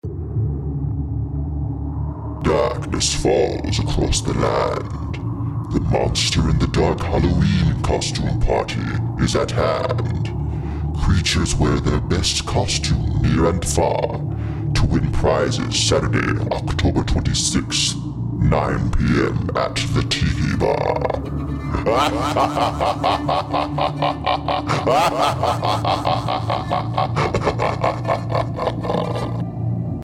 A radio spot informing Naval Station Guantanamo Bay residents of the Halloween Costume Party.